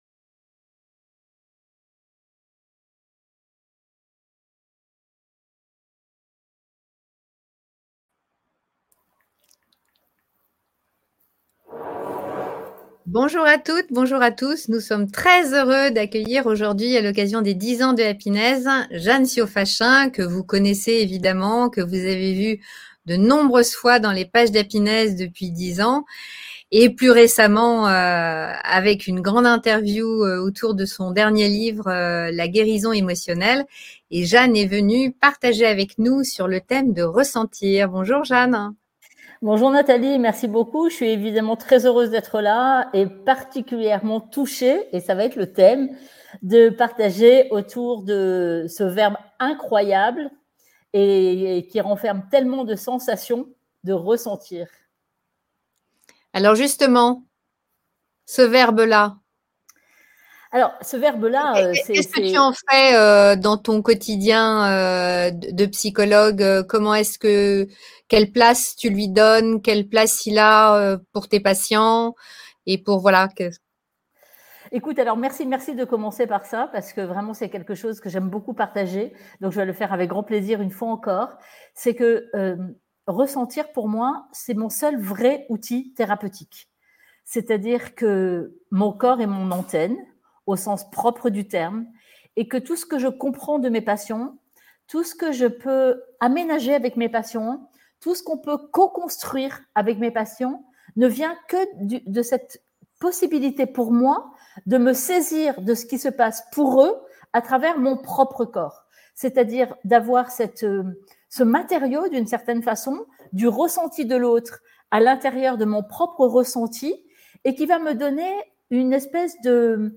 Interview 10 ans